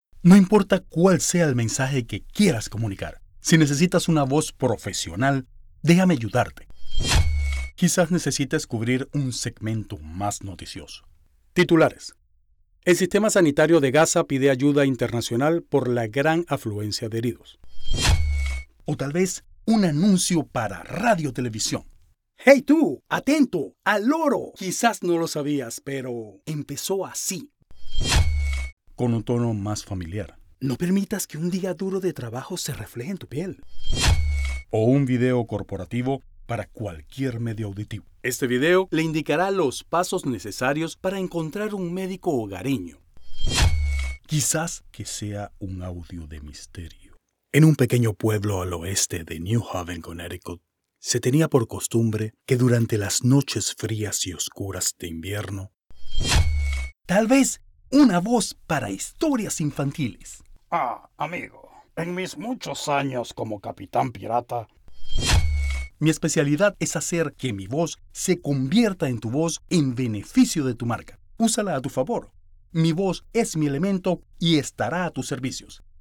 Male
Adult (30-50)
Mi acento nativo es Venezolano, con manejo del acento neutro, mi voz puede ser versátil y agradable, con una buena dicción y conocimiento profesional y técnico de la locucion, junto a la creatividad, flexibilidad y empatía con tus ideas.
Radio Commercials
All our voice actors have professional broadcast quality recording studios.